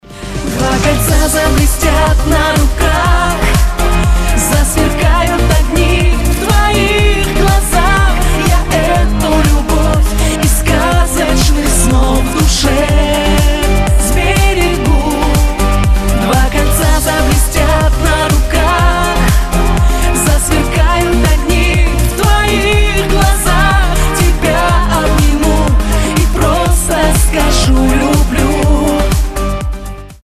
• Качество: 256, Stereo
поп
dance